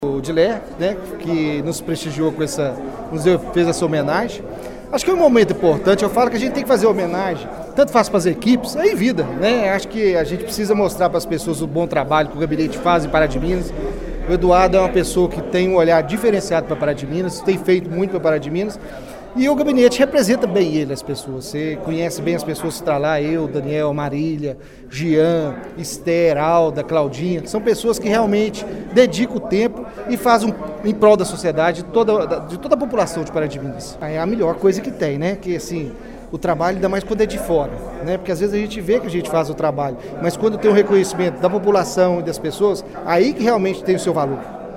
Assim foi a quinta-feira (09) na Câmara de Vereadores de Pará de Minas, que realizou uma sessão solene para entrega de Moções de Aplausos e afixação de retratos na Galeria dos Benfeitores de Pará de Minas e também na Galeria de Vereadoras.